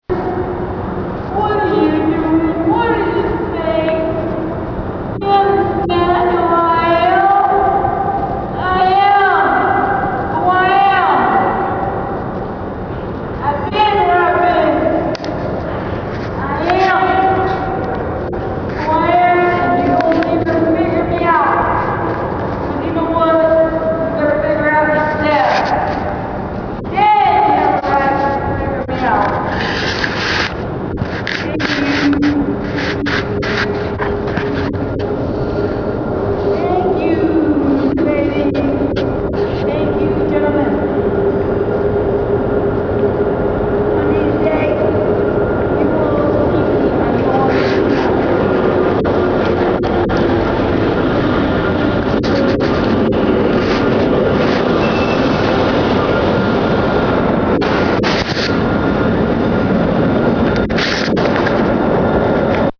bus-tunnel.mp3